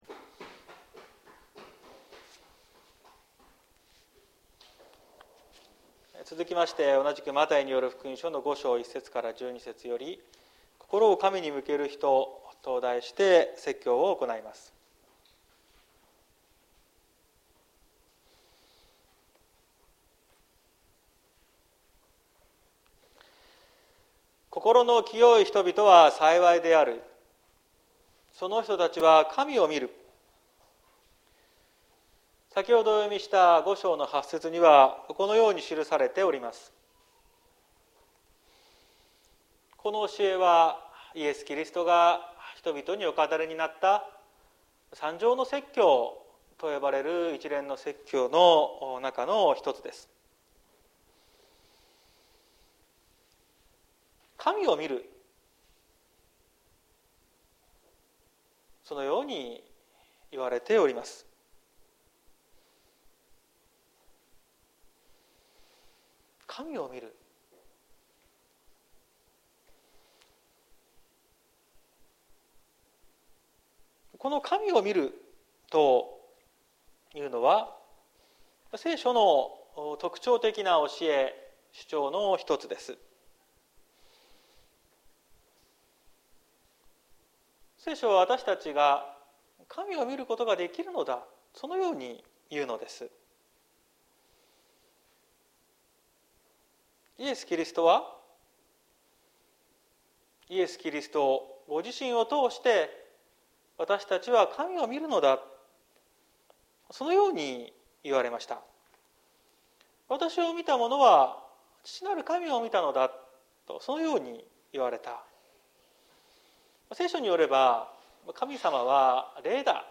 2022年08月07日朝の礼拝「心を神に向ける人」綱島教会
説教アーカイブ。